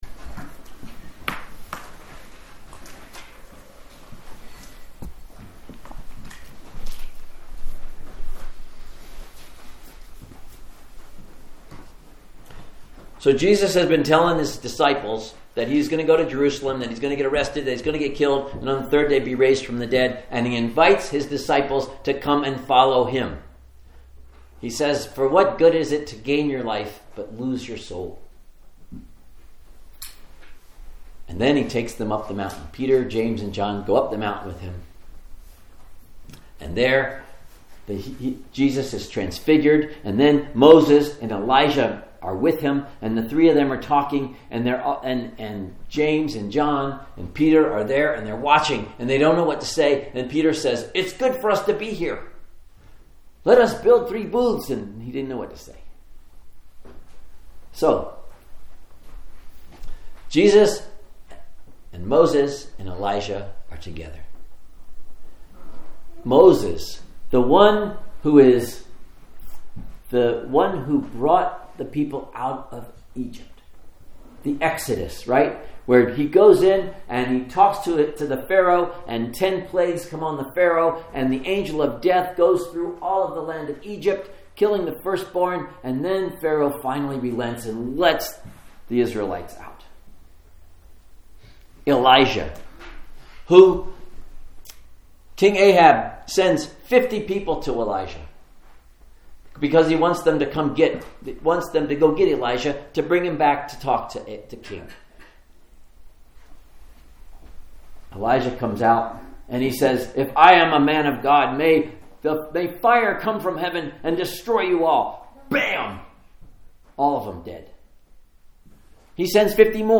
Sermons | Lake Chelan Lutheran Church